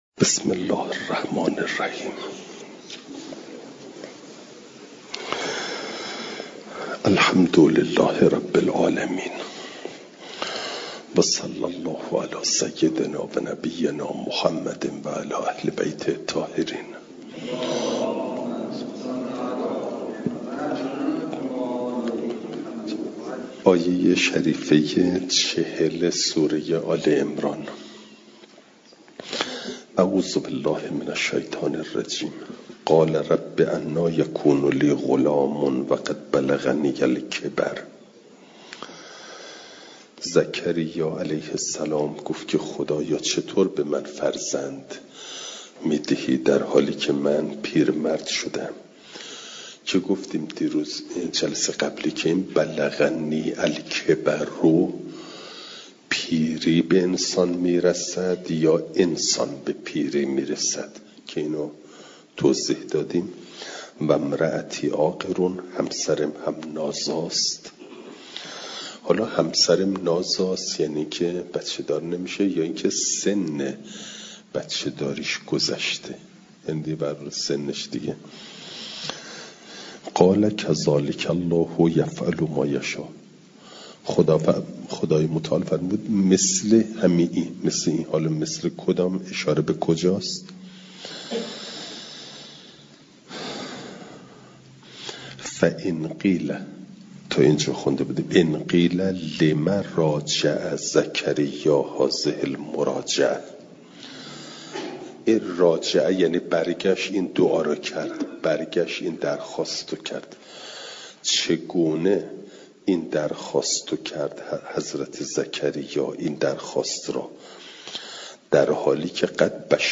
فایل صوتی جلسه دویست و هفتادم درس تفسیر مجمع البیان